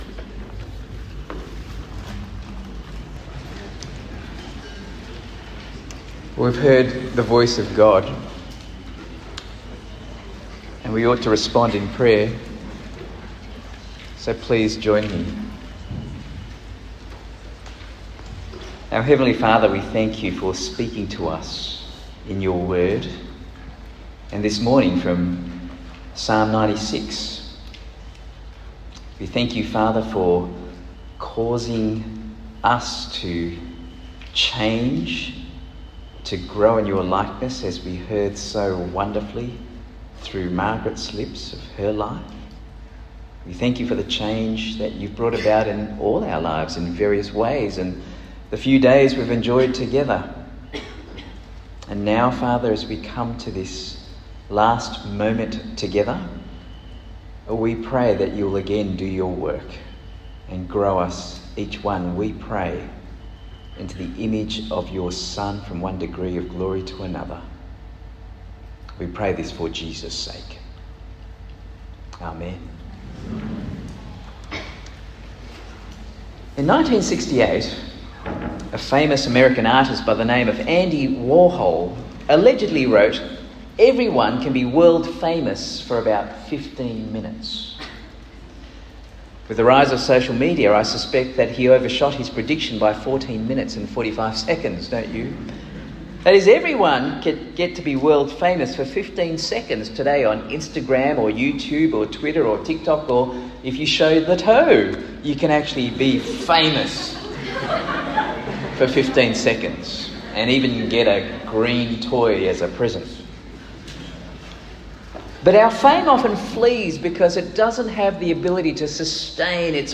Talk 6 of 6 given at Launch 2025, a camp for school leavers keen to live for Jesus as they commence university life.